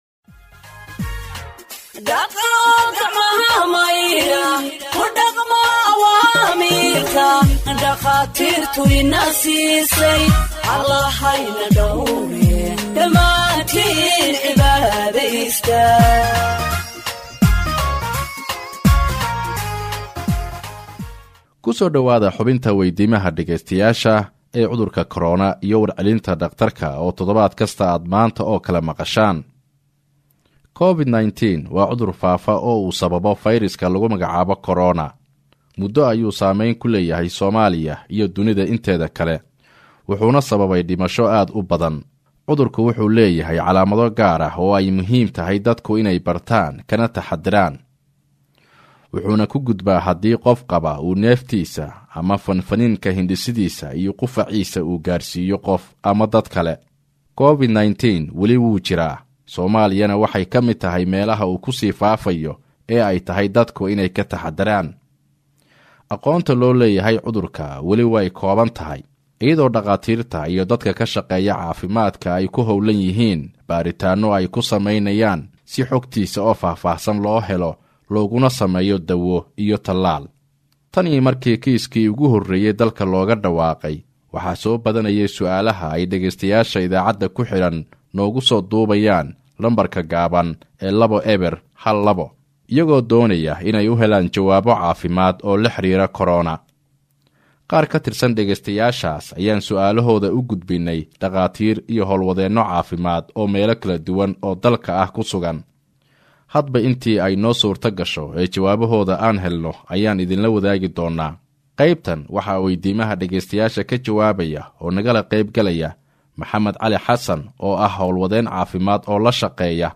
Health expert answers listeners’ questions on COVID 19 (40)
HEALTH-EXPERT-ANSWERS-LISTENERS-QUESTIONS-ON-COVID-19-40.mp3